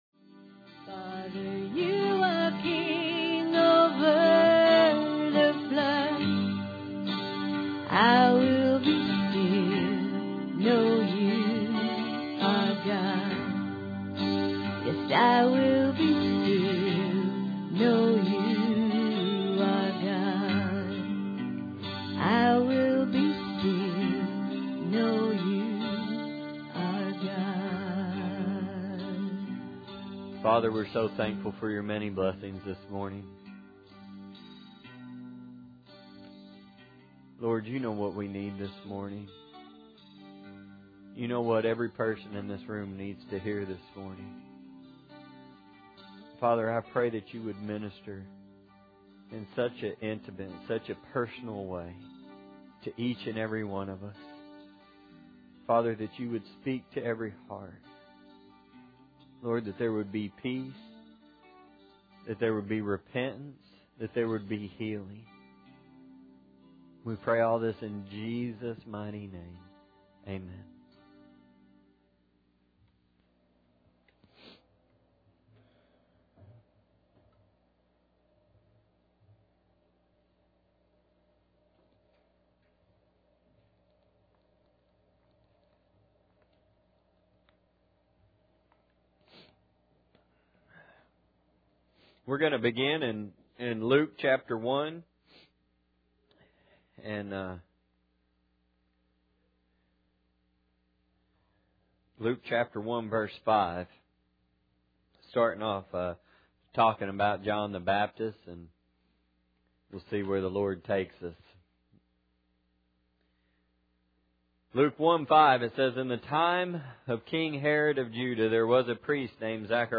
Luke 1:5- Service Type: Sunday Morning Bible Text